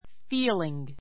feeling 中 A1 fíːliŋ ふィ ーりン ぐ 動詞 feel の-ing形 （現在分詞・動名詞） 名詞 ❶ 感覚 , 感じ I have a feeling that something good is going to happen.